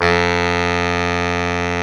SAX B.SAX 0U.wav